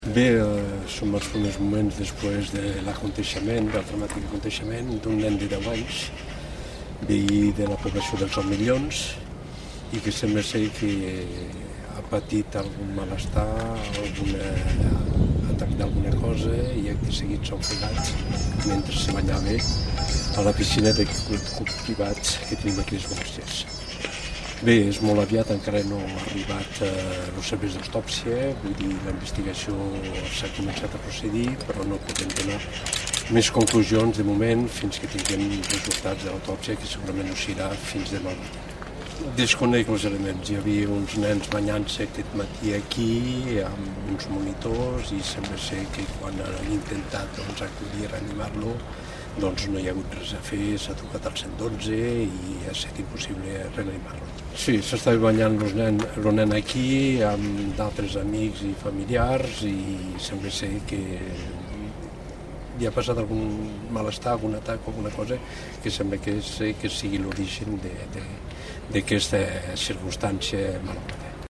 Les primeres declaracions de l'alcalde de les Borges, Enric Mir, al lloc dels fets.